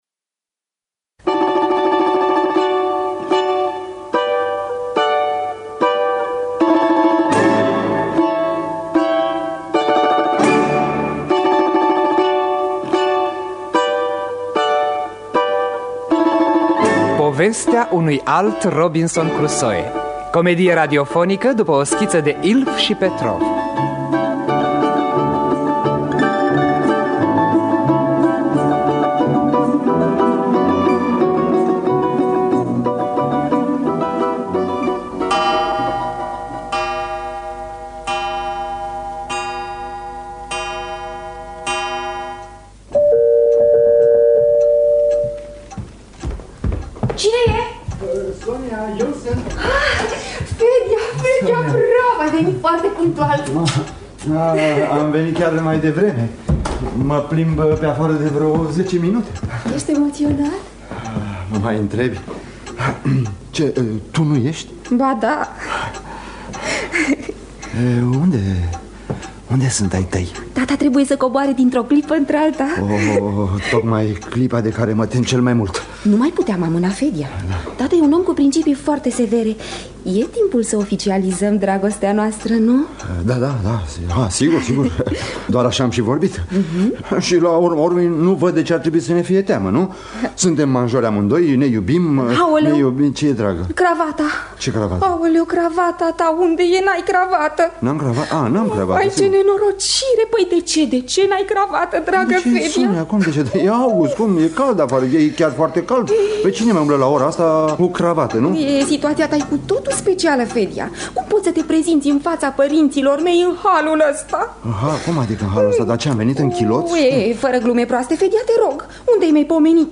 – Teatru Radiofonic Online